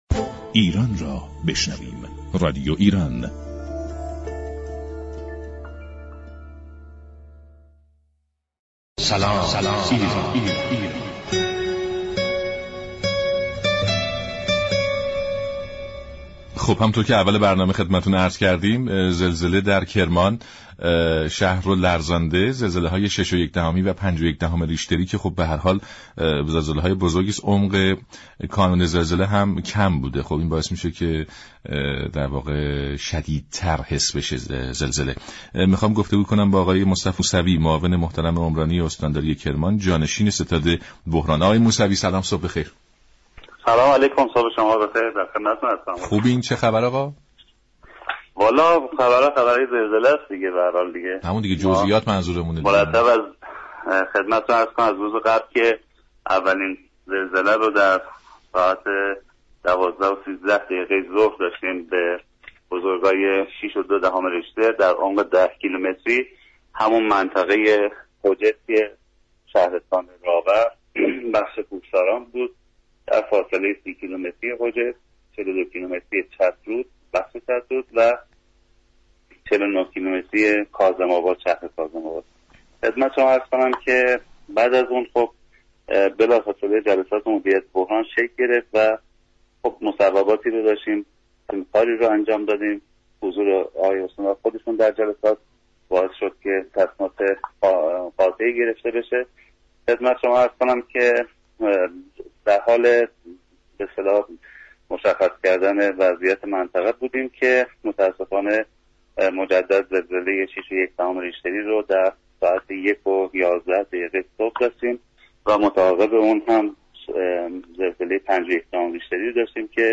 معاون عمرانی استانداری كرمان و جانشین ستاد بحران در گفت و گو با رادیو ایران گفت: از آنجا كه در جهت ایمن و مقاوم سازی ساختمان اقدامات زیادی شده؛ میزان تلفات به میزان زیادی كاهش یافته است.